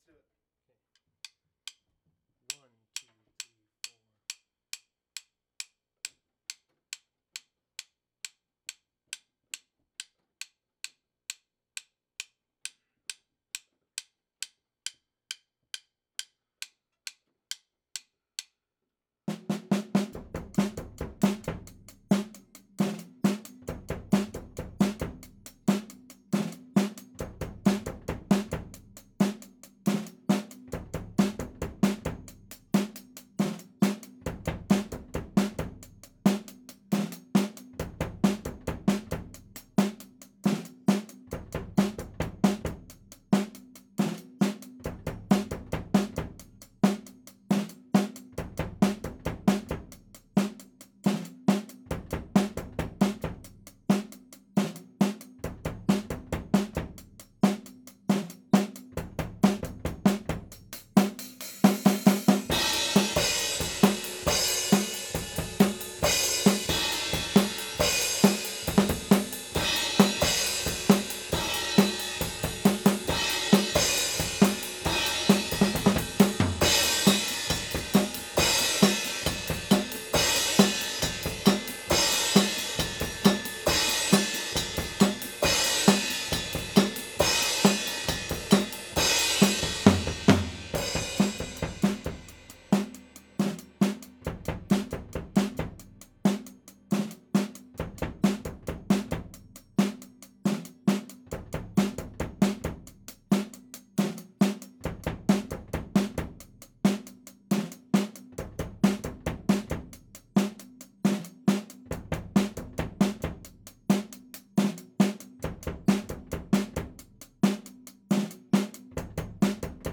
Faith-Overheads (2.1).wav